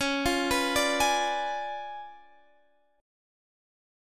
Listen to Db7b9 strummed